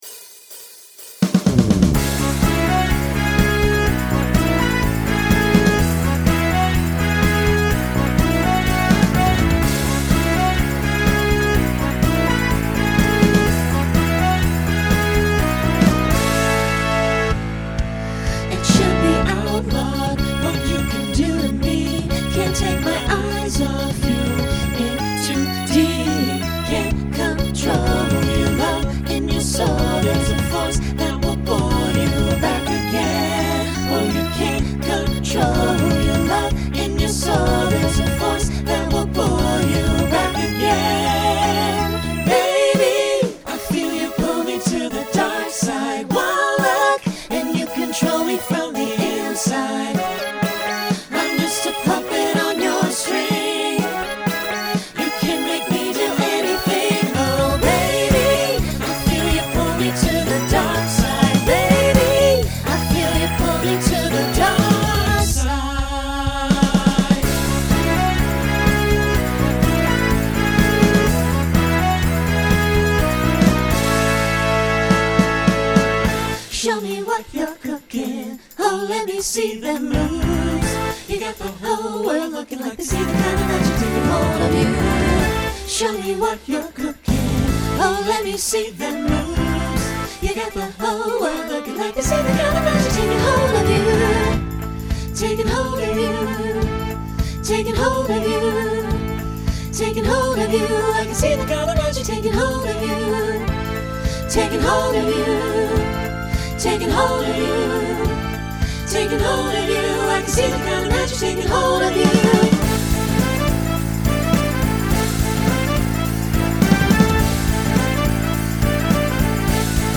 Pop/Dance , Rock Instrumental combo
Voicing SATB